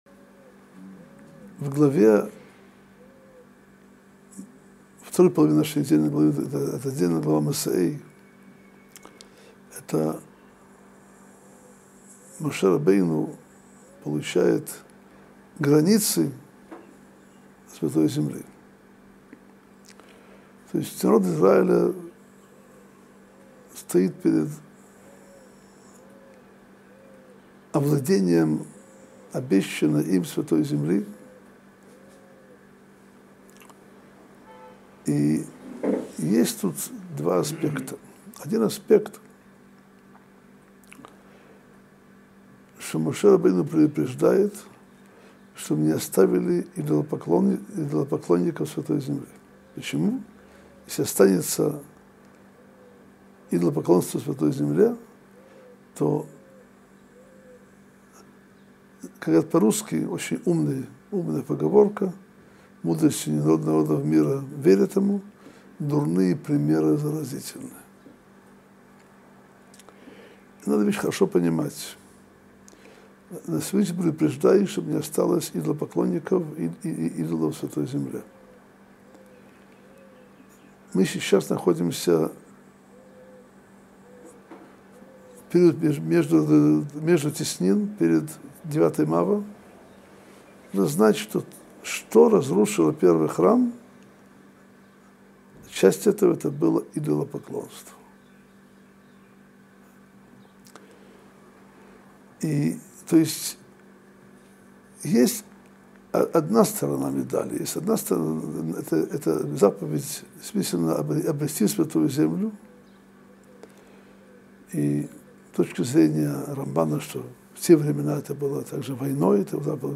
Содержание урока: Что разрушило Второй Храм? Как нужно жить в Израиле? За что мы должны Благодарить Всевышнего? Почему так важен «фундамент»?